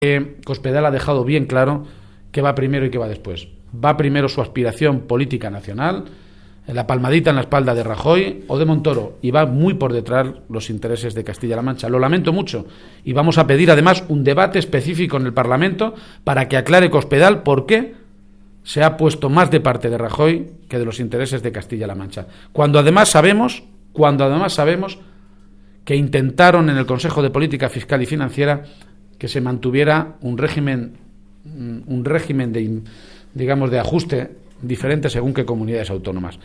García-Page inauguró en la capital conquense una Jornada de Política Municipal organizada por el PSOE de Cuenca y a la que asistieron más de 200 alcaldes y concejales de esta provincia